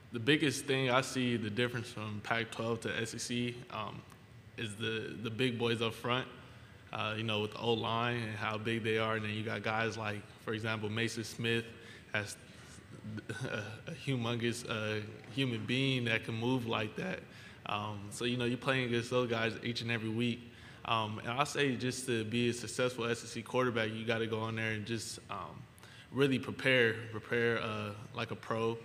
Louisiana State starting quarterback Jayden Daniels spoke Monday at the first day of SEC Media Days.